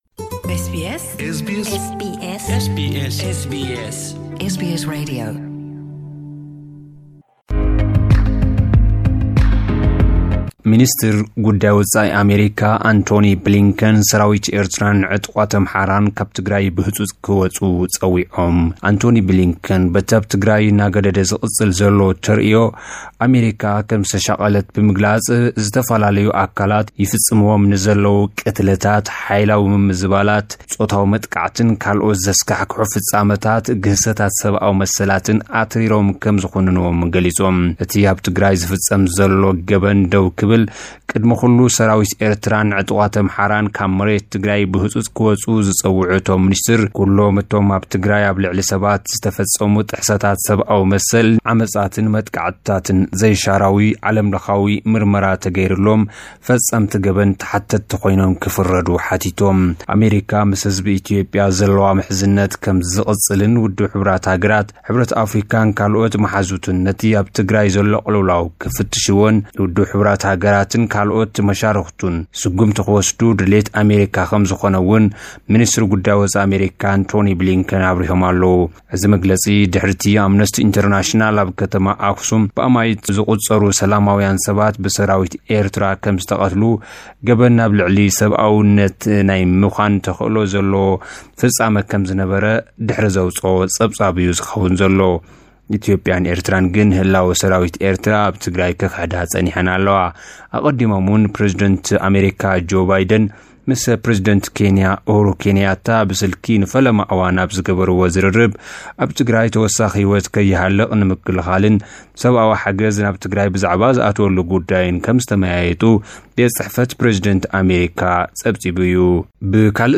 ኣሜሪካ ሰራዊት ኤርትራን ዕጡቓት ኣምሓራን ካብ ትግራይ ክወጹ ጸዊዓ፣ (ሓጸርቲ ጸብጻባት)